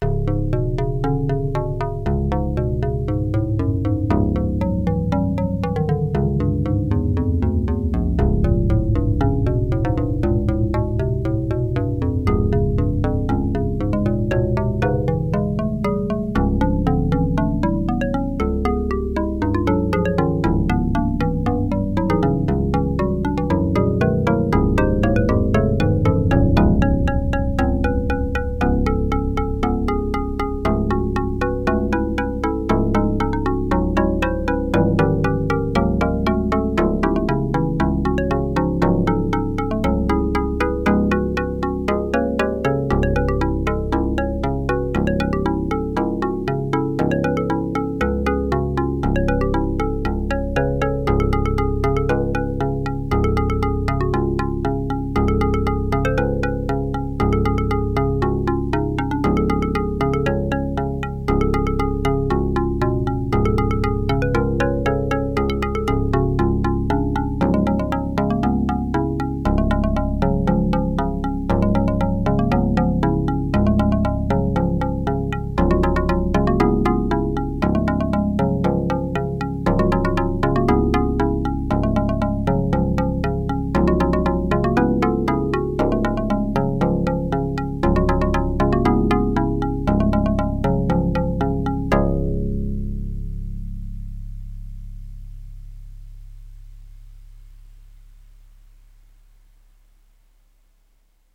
Xarp-61 (pronounced "harp") is a 61 string harp of Karplus-Strong string models.
using only a Xarp-61 instrument.
There was no other processing.